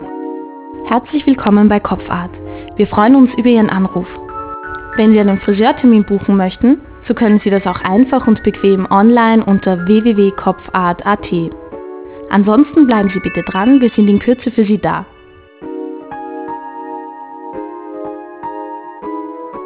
Ihre Telefonanlage informiert ihn mit einer individuell gestalteten Ansage und Musikuntermalung